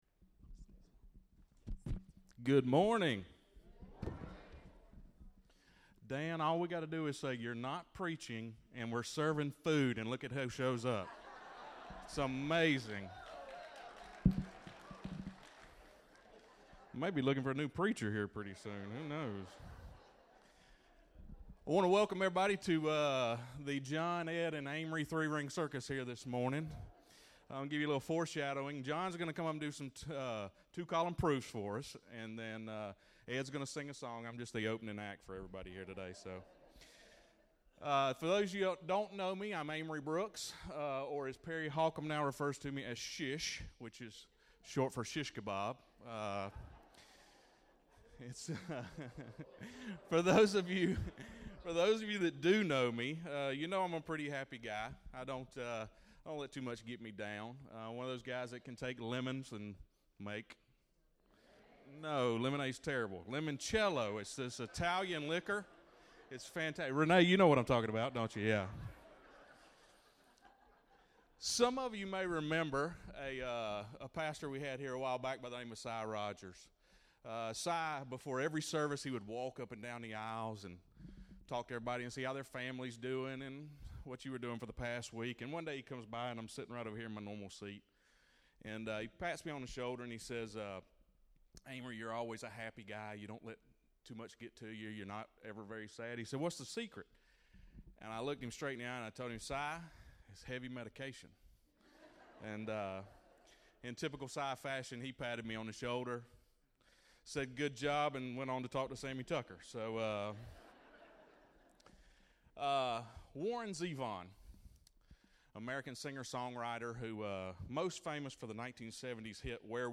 Testimonies - Bethesda UMC
3 members’ testimonies, there are breaks between each one.